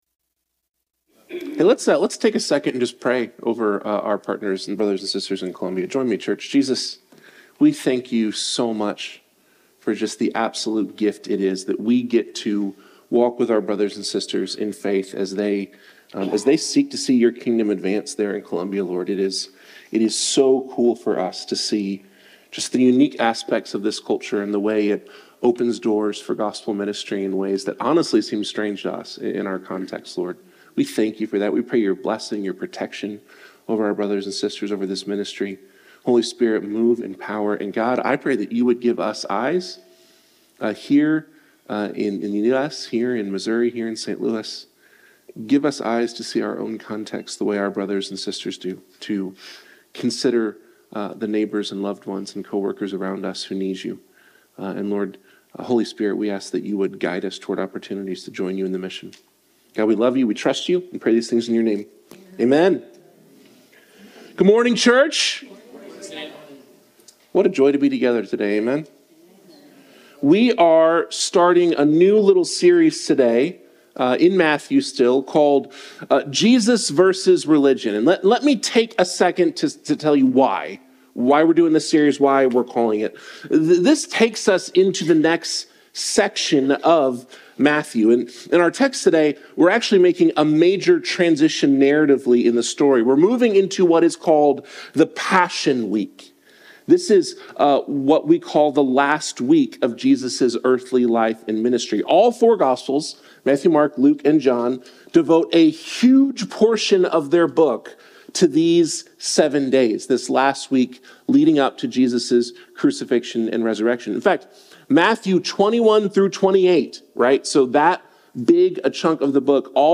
He encouraged the congregation to trust Jesus, asserting that despite their struggles, they can find hope and healing in Him, as He is humble and present in their pain.